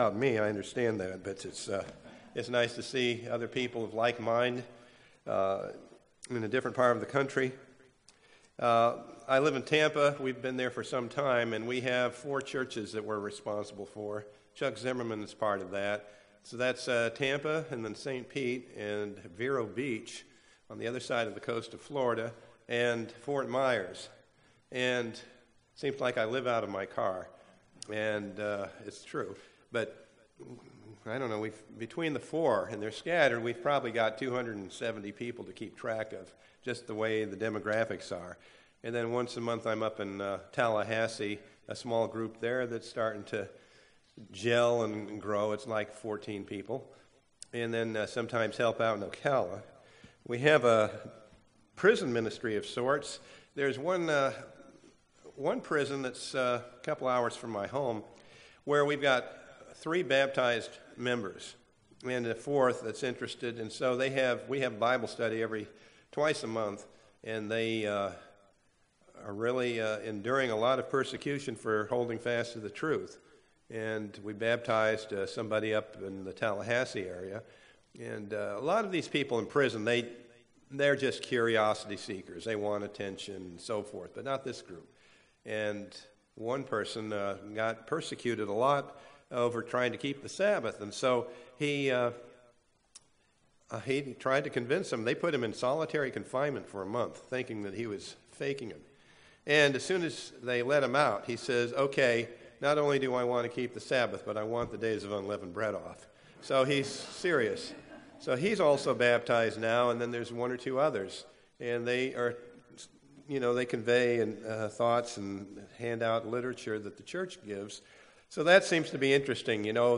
View on YouTube UCG Sermon Studying the bible?